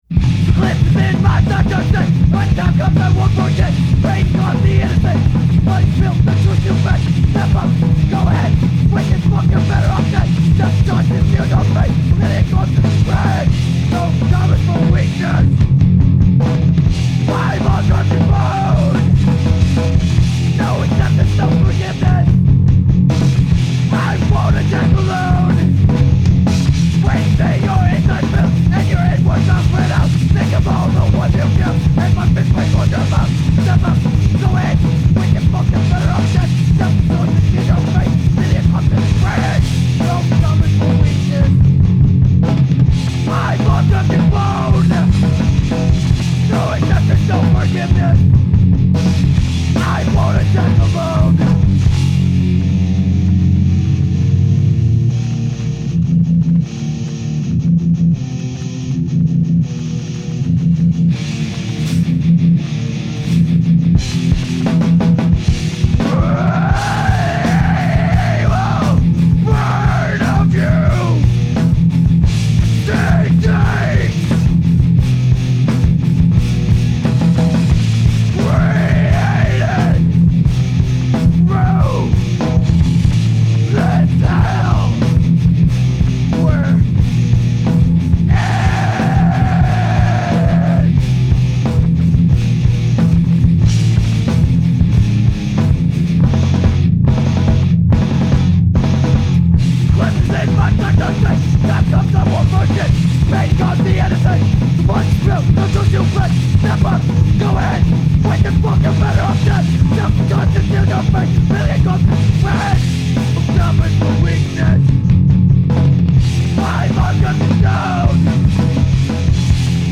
Background Screaming
Vocals
Bass
Guitar
Drums
Hardcore , Straight Edge